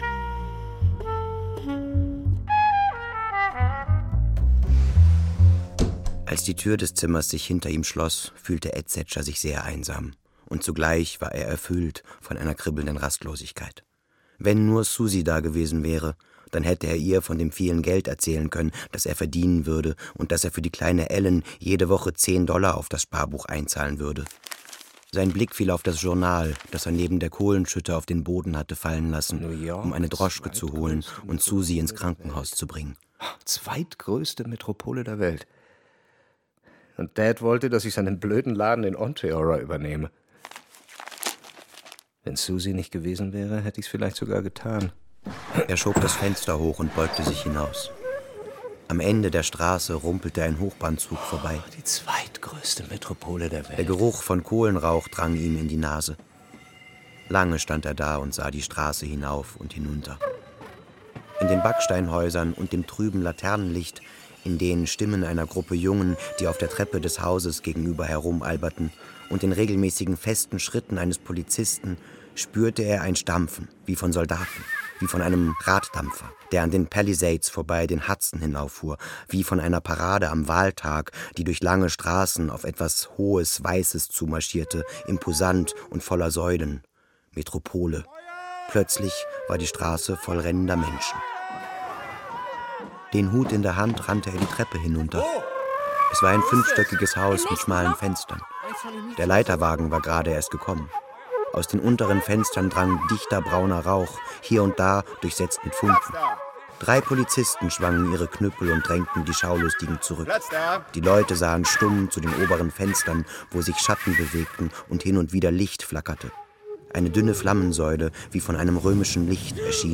Manhattan Transfer - John Dos Passos - Hörbuch